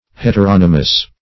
Heteronomous \Het`er*on"o*mous\, a. [Hetero- + Gr. no`mos law.]